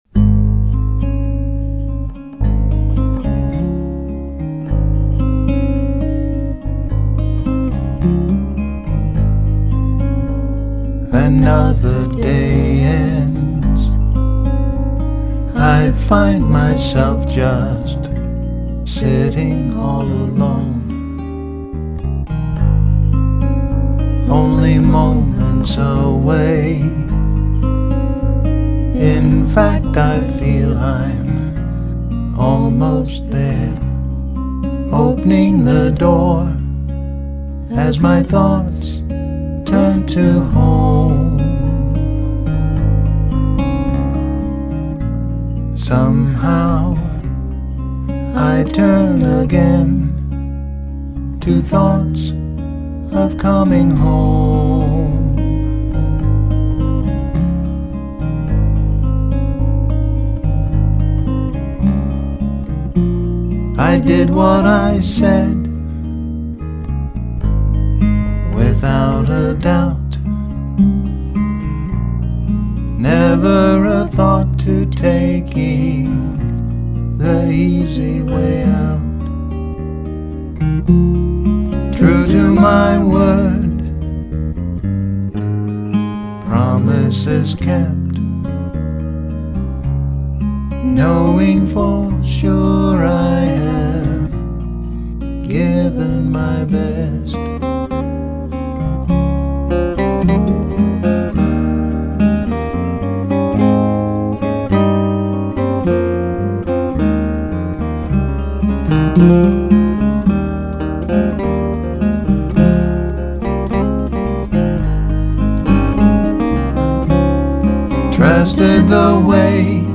guitar and vocals